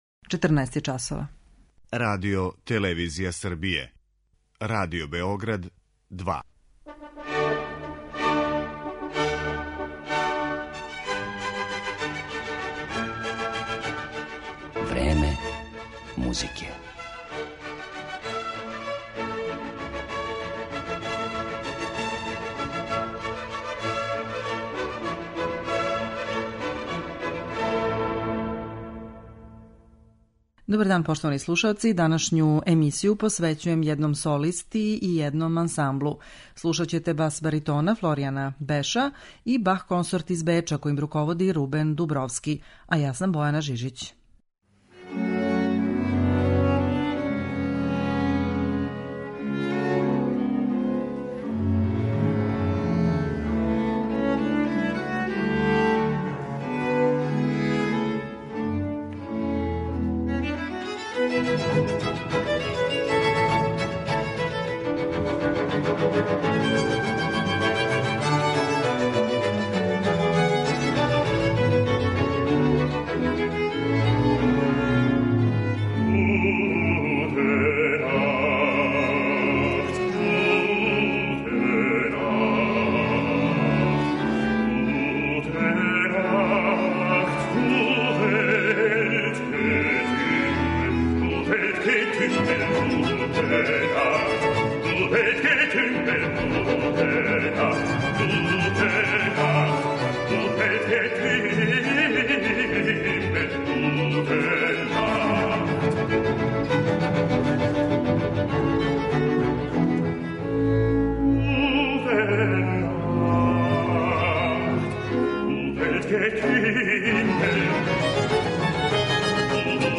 'Путеви до Баха' - наслов је пројекта који ћете моћи да слушете у данашњој емисији 'Време музике' и који су заједнички осмислили и реализовали чланови ансамбла Бах консорт из Беча и баритон Флоријан Беш.
Ови врхунски специјалисти за музику барока су желели да осветле стваралаштво неких претходника Јохана Себастијана Баха, који су својим стилом и поступцима најавили оно што ће велики барокни мајстор довести до непревазиђеног врхунца.